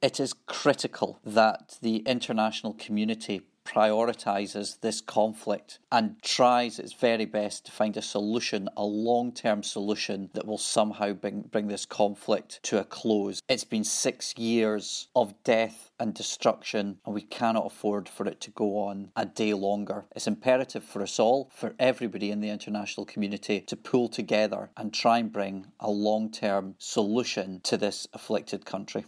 SNP MP Stephen Gethins speaks out about US action in Syria